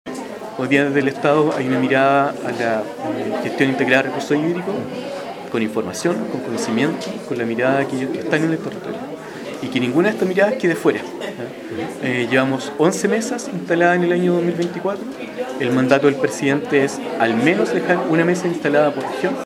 En el marco del Día Mundial del Agua, cada 22 de marzo, el Centro de Recursos Hídricos para la Agricultura y la Minería, Crhiam, organizó una nueva edición del Foro del Agua, con el título “Salvemos nuestros glaciares”.
Desde el Estado, Rodrigo Sanhueza, director general de Aguas, explicó que el objetivo es gestionar de manera integrada los recursos hídricos del país.